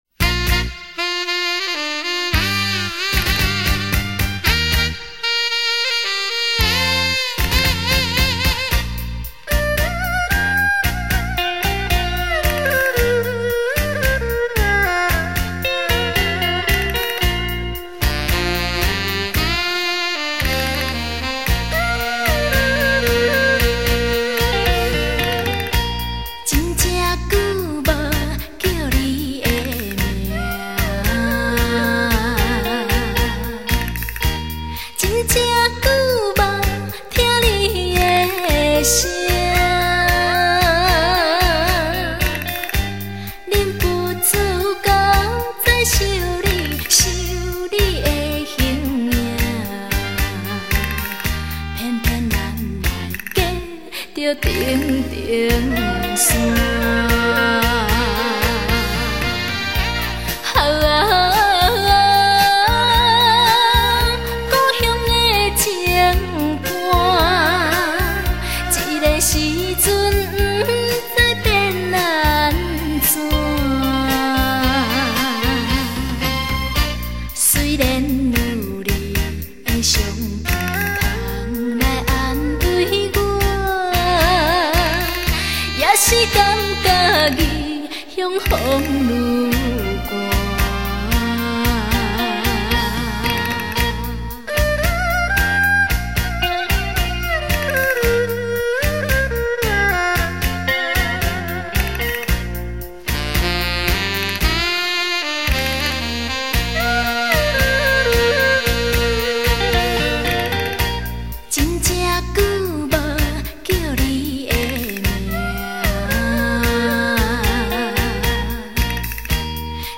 一首温婉动人心的台湾情歌
轻快的编曲
表现青少年动的玩味音乐